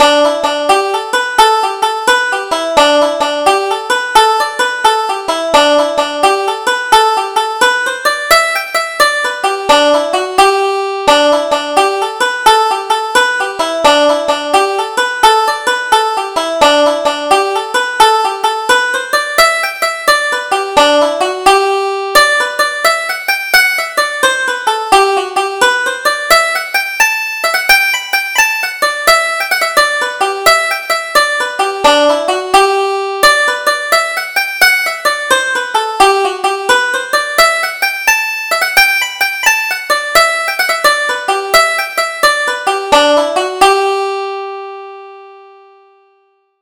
Double Jig: Farewell Sweet Nora